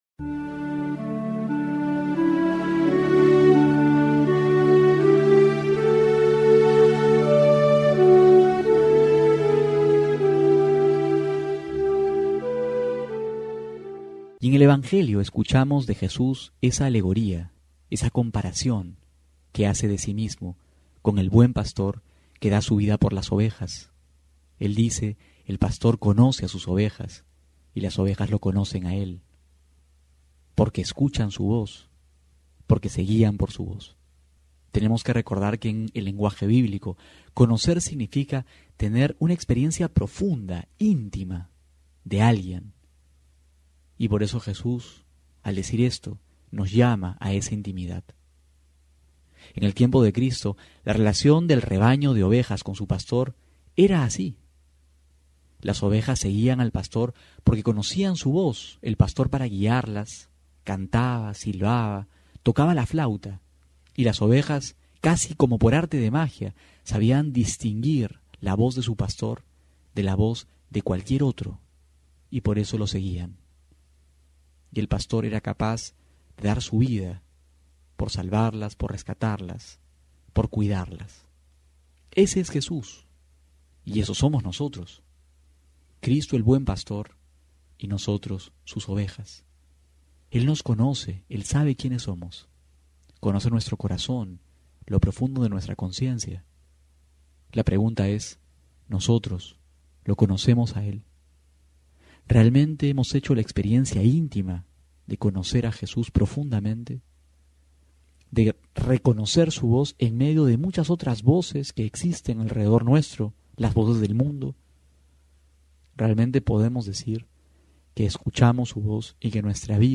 abril29-12homilia.mp3